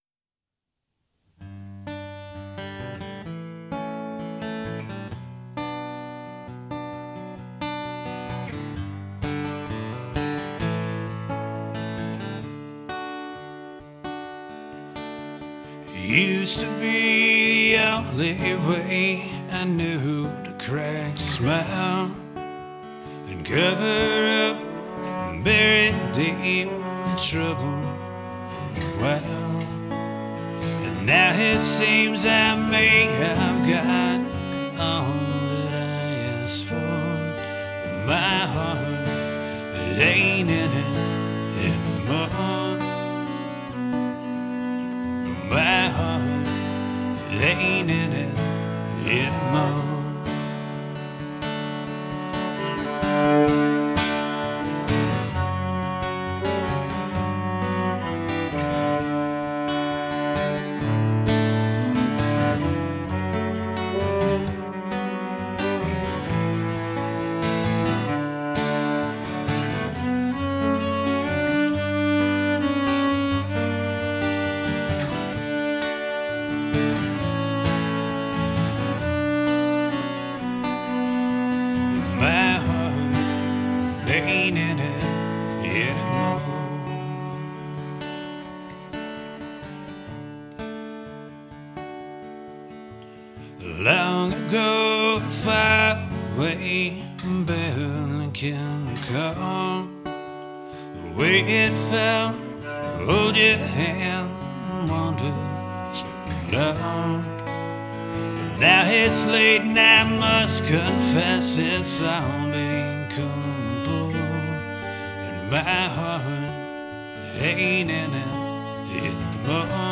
bass guitar
lead guitar
fiddle, mandolin, acoustic guitar,vocals
drums, vocals